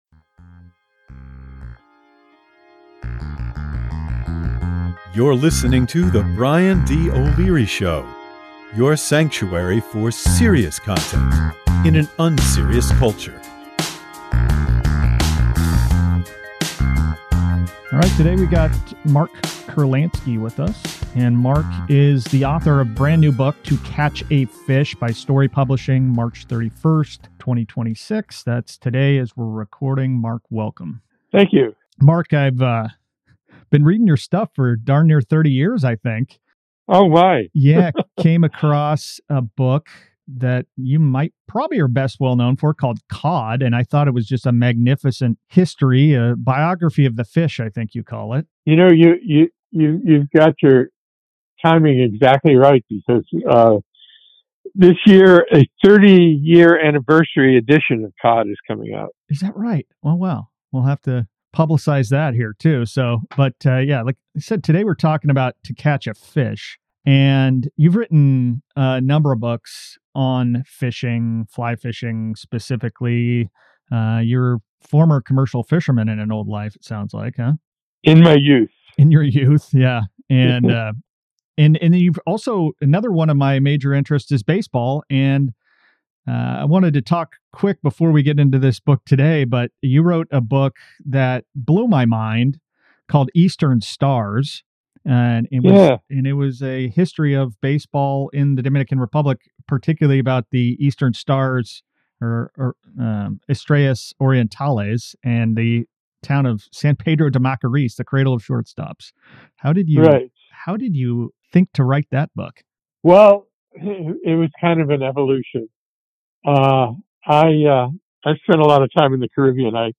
TO CATCH A FISH with author Mark Kurlansky
Mark Kurlansky on The Dying Art of Catching Real Fish Guest: Mark Kurlansky